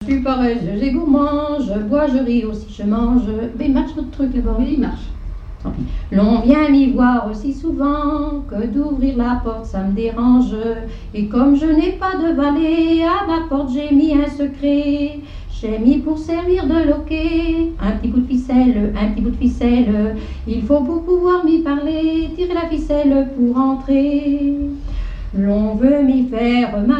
airs de danses et chansons traditionnelles
Pièce musicale inédite